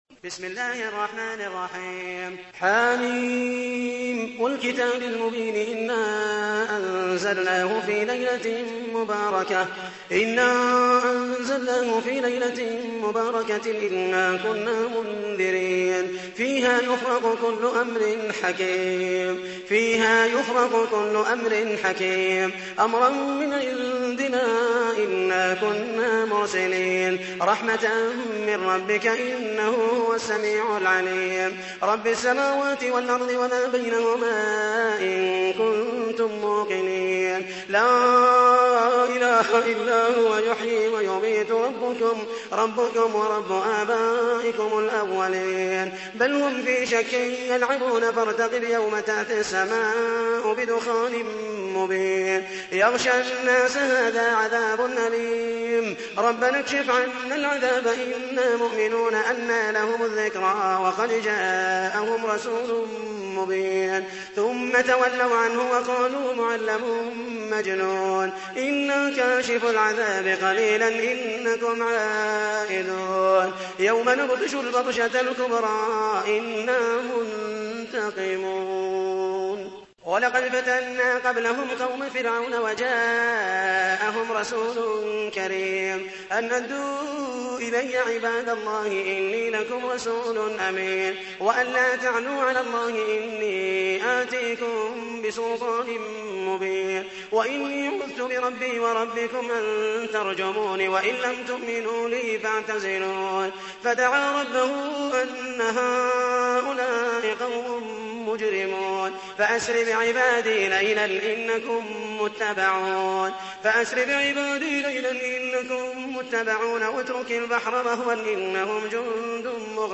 تحميل : 44. سورة الدخان / القارئ محمد المحيسني / القرآن الكريم / موقع يا حسين